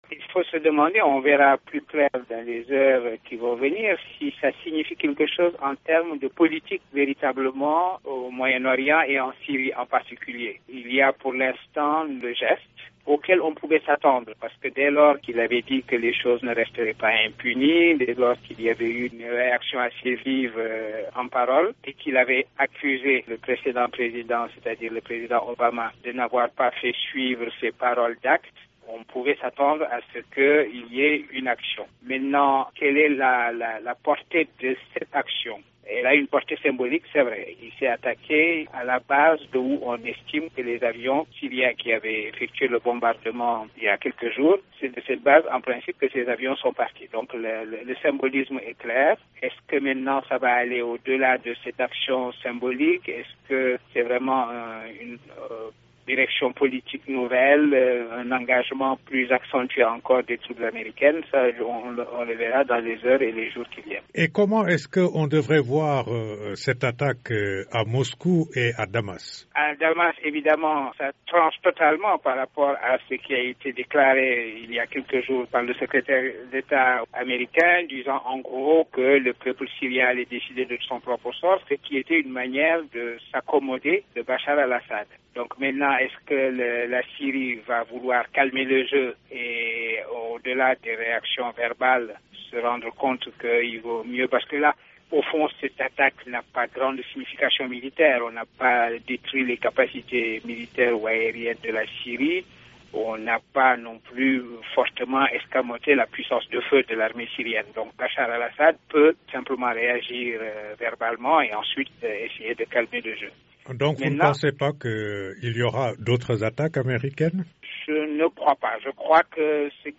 Herman Cohen, ancien secrétaire d’Etat-adjoint aux Affaires africaines, au micro